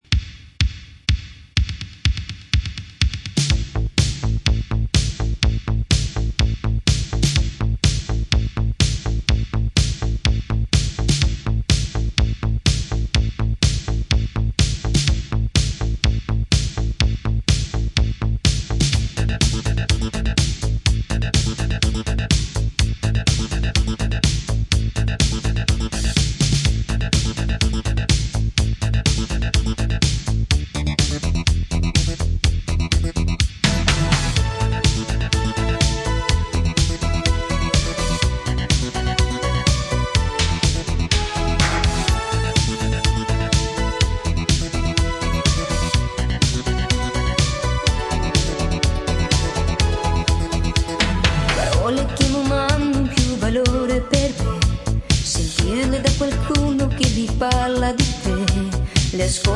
ポジティヴでロマンティックなメロディーにコケティッシュなイタリア語ヴォーカルが映えまくる逸品です。